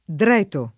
Si tirq ddrL$to el r% ddi fr#n©a] (Machiavelli) — antiq. o pop. tosc. dreto [
dr$to] — dell’uso ant., per dietro (come per entro e sim.), la composiz. con particelle enclitiche: dietrogli [dL$trol’l’i], dietrole [dL$trole], «dietro a lui, a lei», ecc. — cfr. di dietro